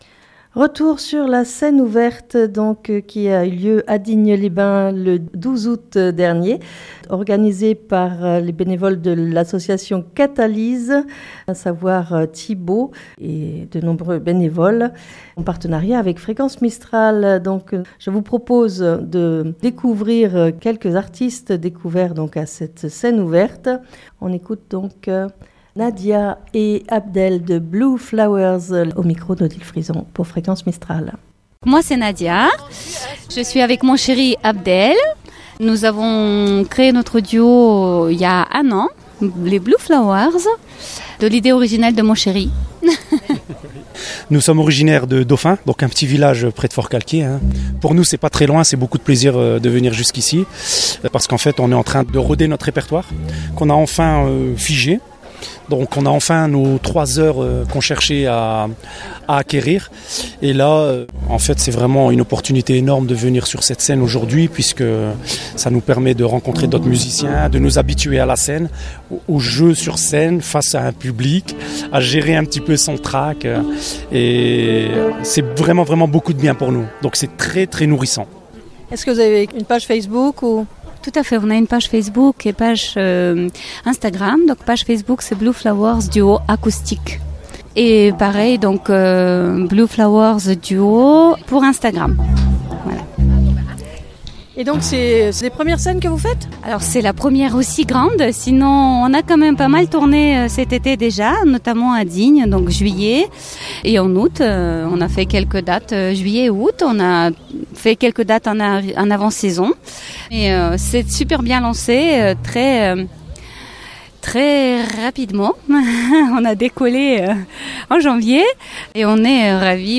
The plaids Dans cette 1ère émission, 4 interviews réalisées sur la place du Général De Gaulle à Digne les Bains le 12 août 2023.